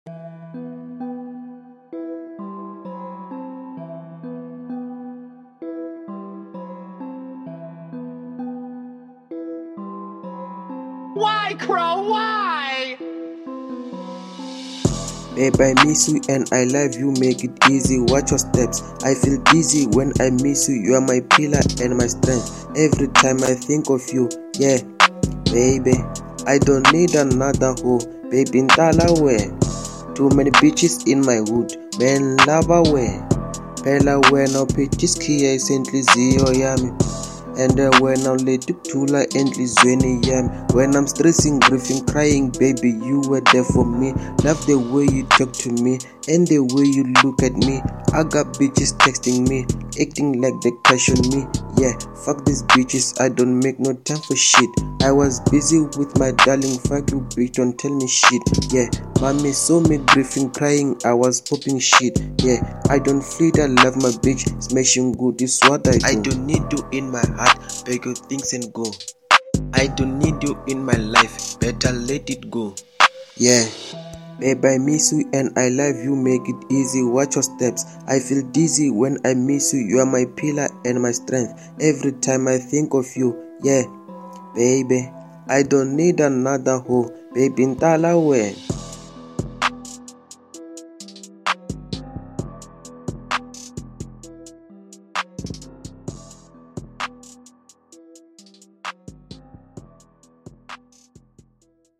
01:42 Genre : Hip Hop Size